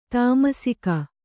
♪ tamasika